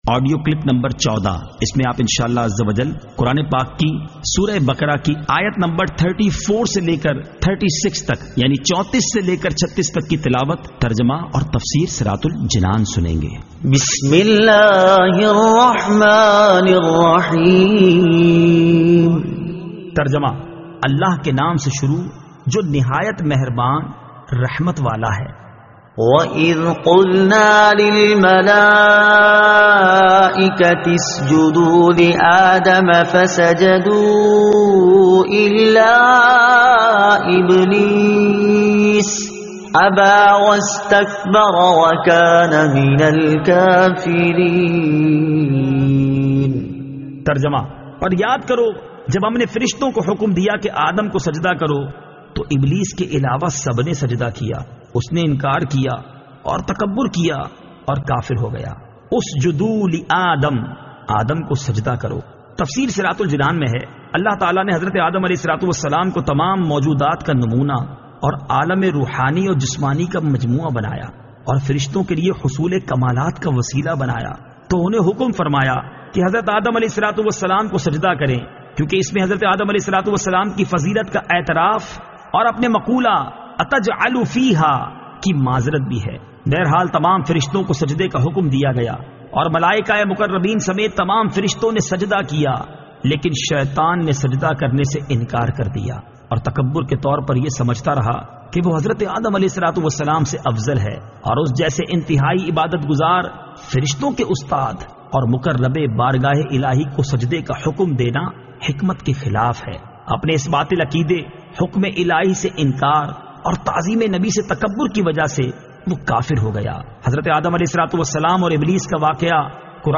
Surah Al-Baqara Ayat 34 To 36 Tilawat , Tarjuma , Tafseer